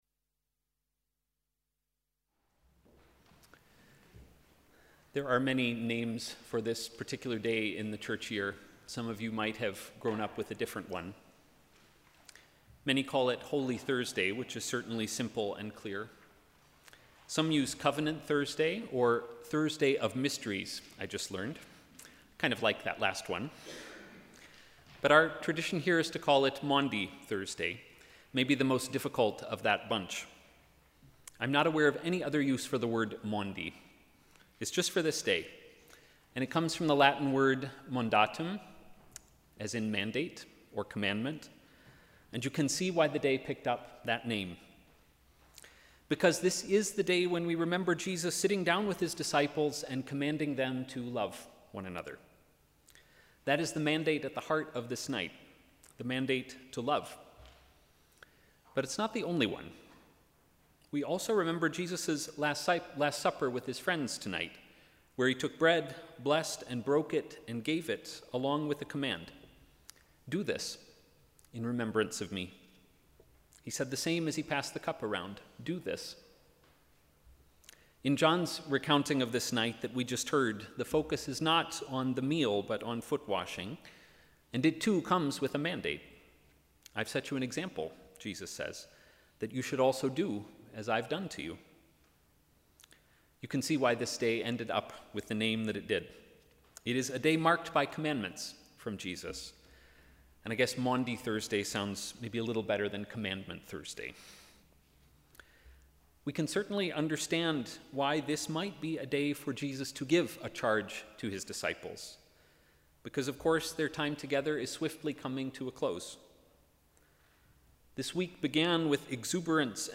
Sermon: ‘Sit down at love’s feast’